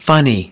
11 funny (adj) /ˈfʌni/ Hài hước